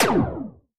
poly_shoot_laser01.wav